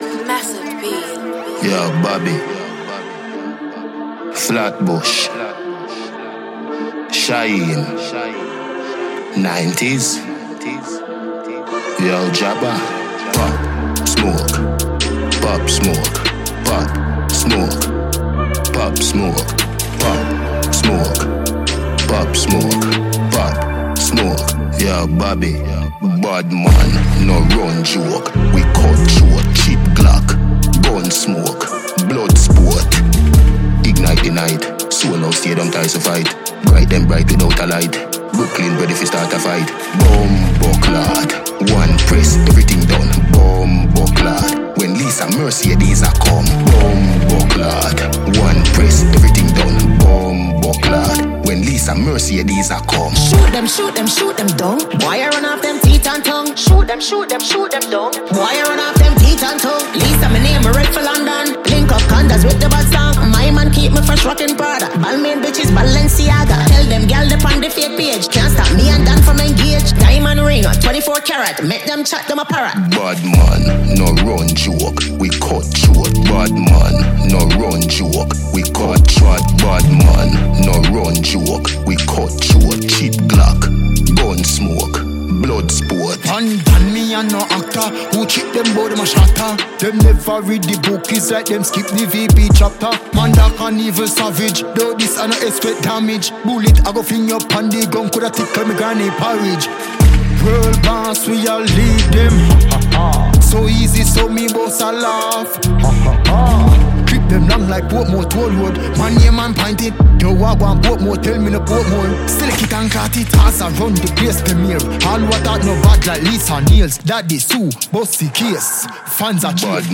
Jamaican Reggae/Dancehall musician